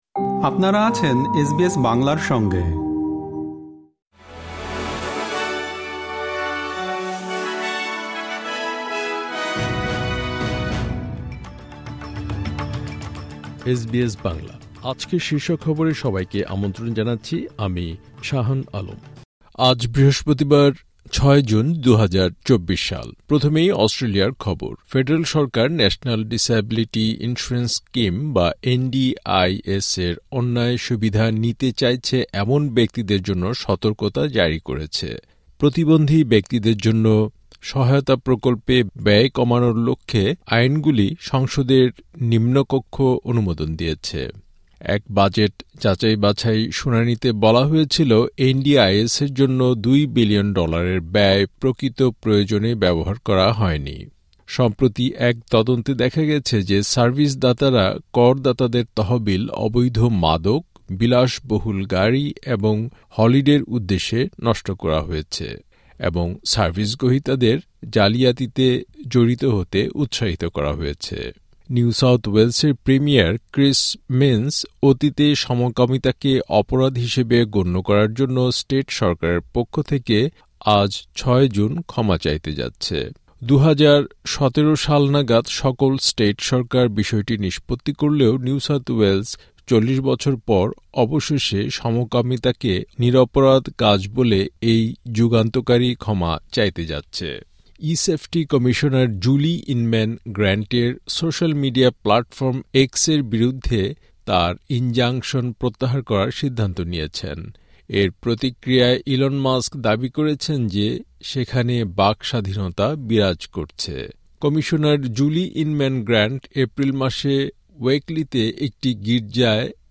এসবিএস বাংলা শীর্ষ খবর: ৬ জুন, ২০২৪